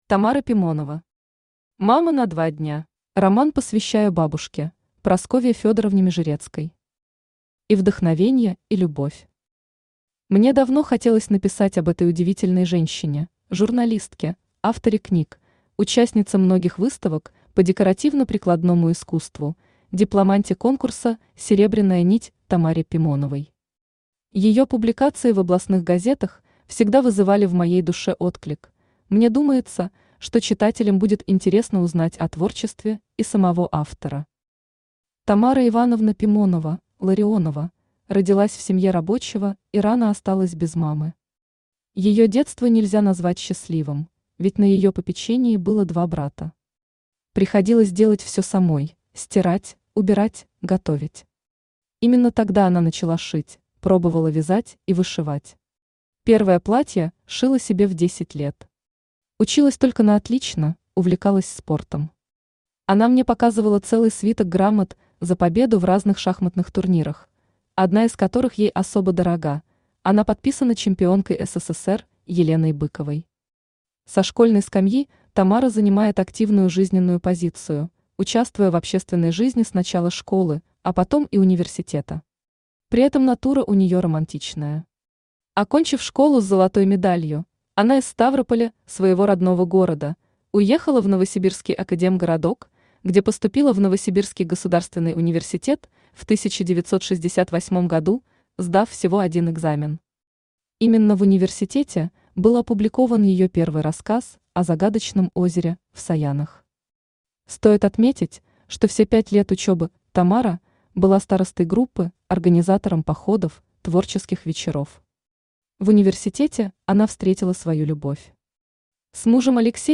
Аудиокнига Мама на два дня | Библиотека аудиокниг
Aудиокнига Мама на два дня Автор Тамара Ивановна Пимонова Читает аудиокнигу Авточтец ЛитРес.